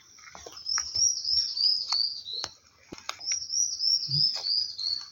Hormiguero Escamoso (Myrmoderus squamosus)
Nombre en inglés: Squamate Antbird
Localidad o área protegida: Pe da Serra do Tabuleiro--estrada Pilões
Condición: Silvestre
Certeza: Observada, Vocalización Grabada